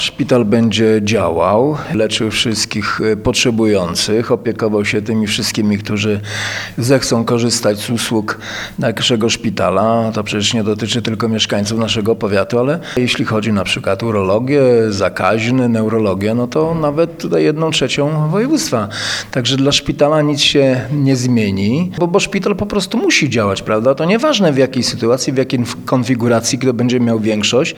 – Wyrok sądu w sporze z Grupą Nowy Szpital nie ma wpływu na funkcjonowanie placówki – zapewnia Wacław Strażewicz, starosta giżycki.